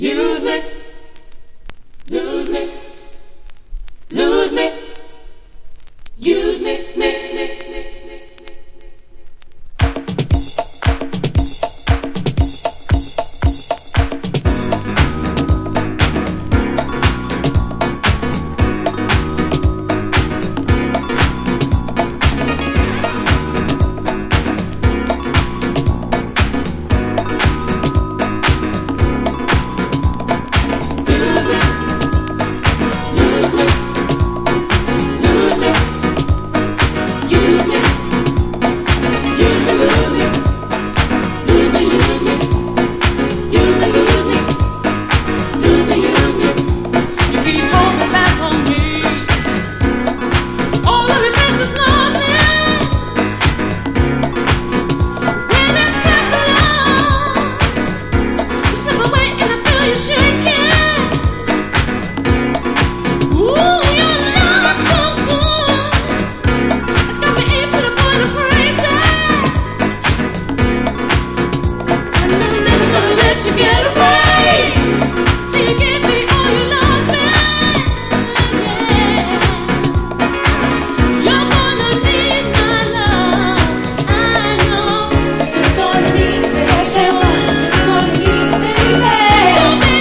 キャッチーで踊れること間違いなし！！